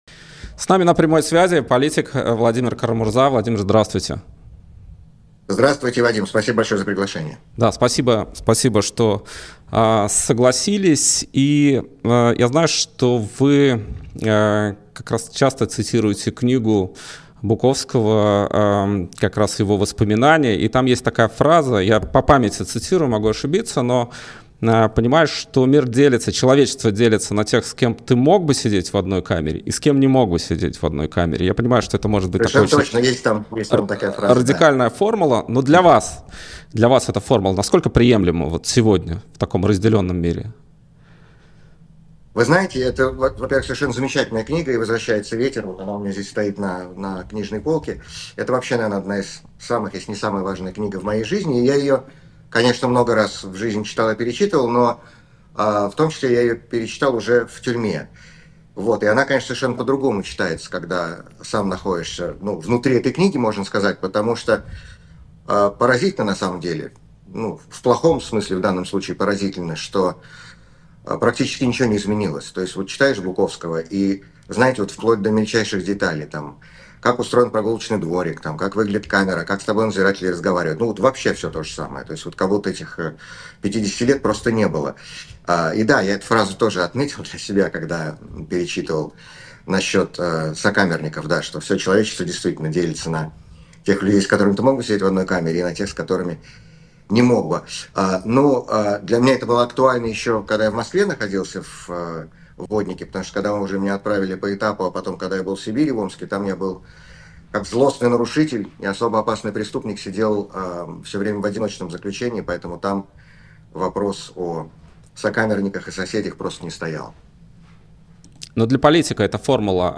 «И грянул Грэм» с Владимиром Кара-Мурзой: Интервью, которое стоит вашего времени